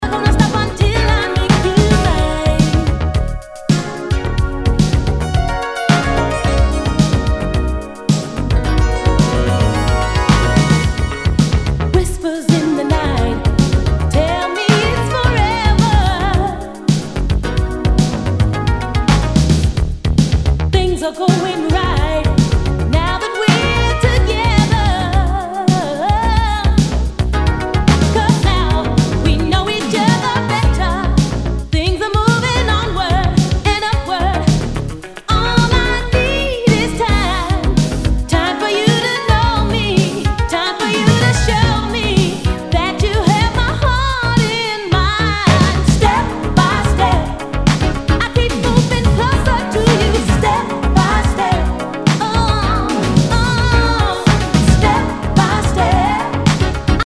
SOUL/FUNK/DISCO